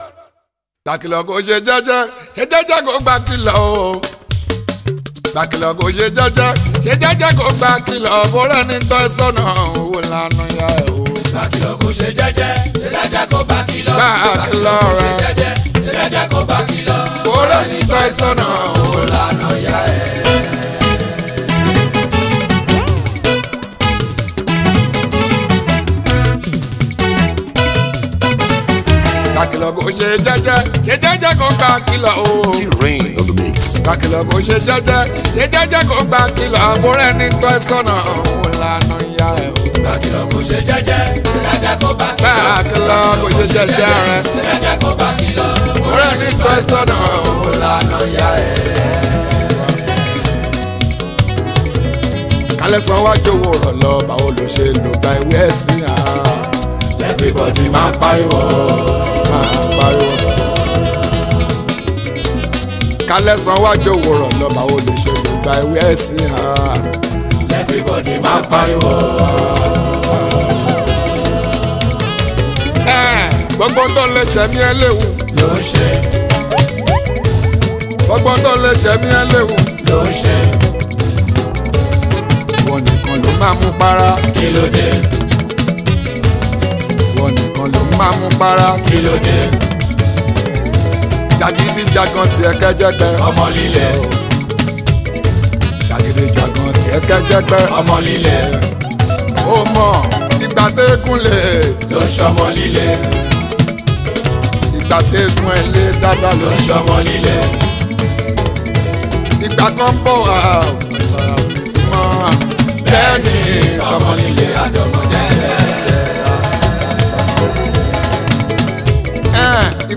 Fuji Music